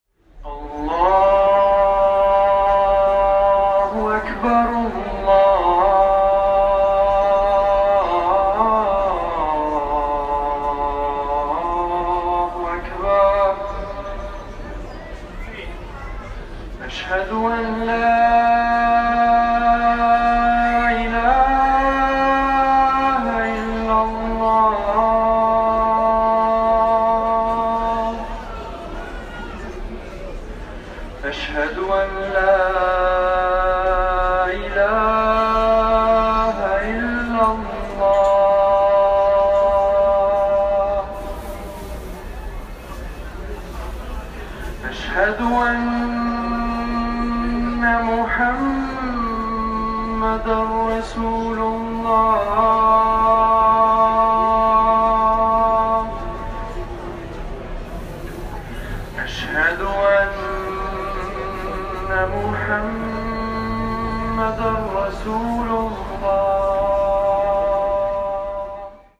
Auf der anderen Straßenseite ragen die Minarette der Moschee in den Abendhimmel.
Muezzin geweckt wird. In unserem Fall um 4.15 Uhr…
Muezzin-Kurz-1.m4a